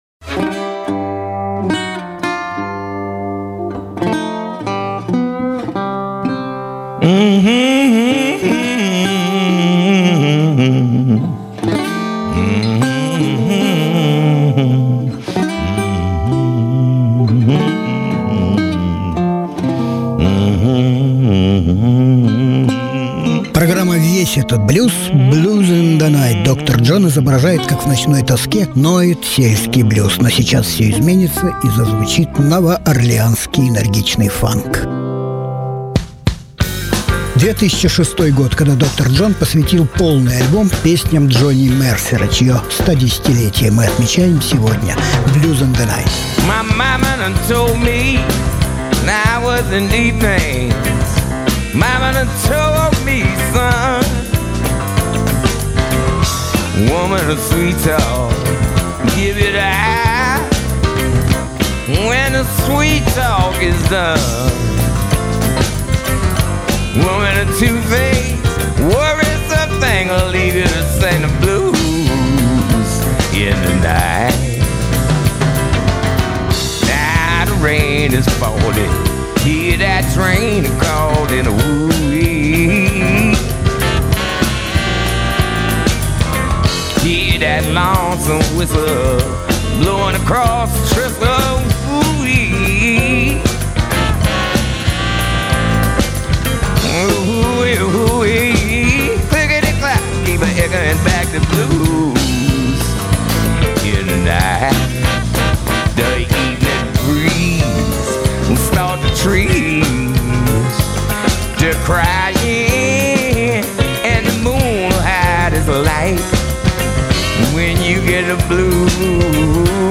Жанр: Блюзы,джаз